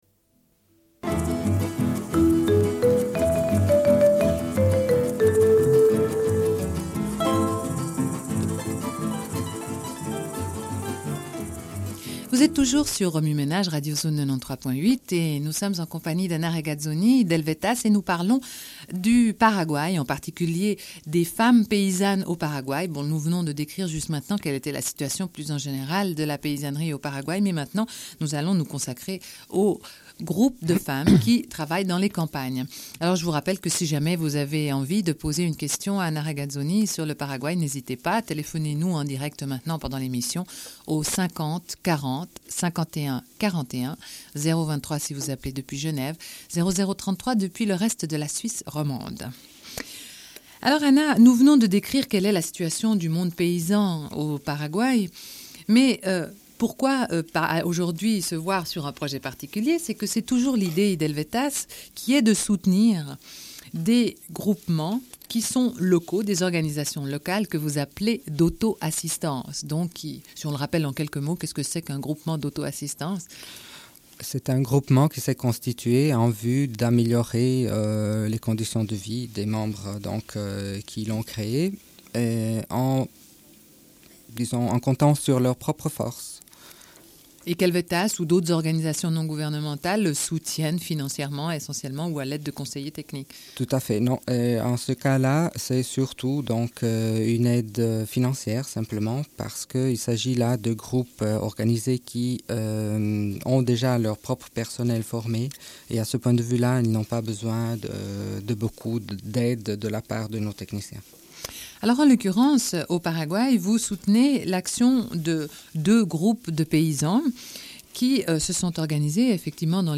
Une cassette audio, face B00:29:01